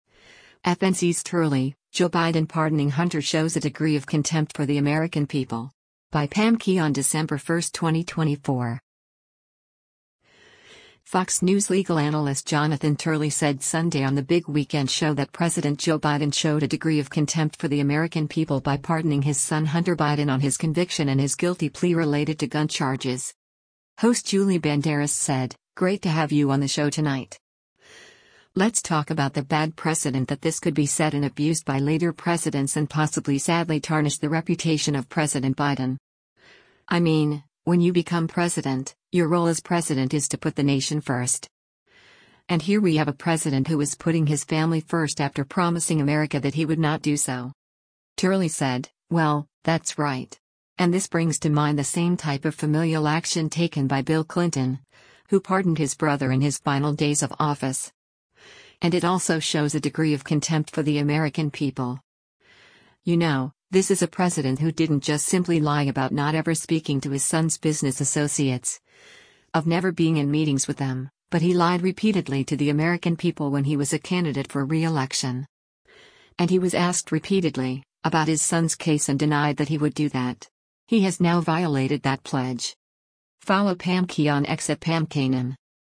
Fox News legal analyst Jonathan Turley said Sunday on “The Big Weekend Show” that President Joe Biden showed “a degree of contempt for the American people” by pardoning his son Hunter Biden on his conviction and his guilty plea related to gun charges.